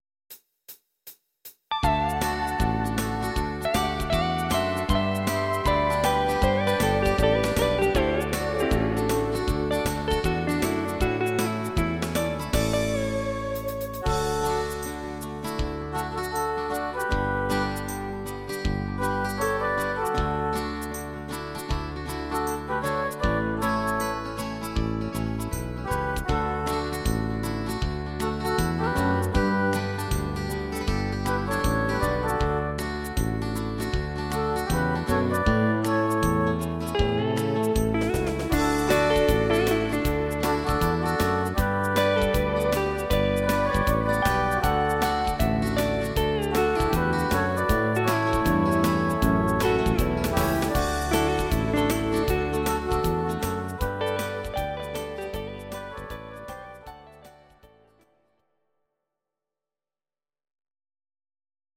These are MP3 versions of our MIDI file catalogue.
Please note: no vocals and no karaoke included.
cover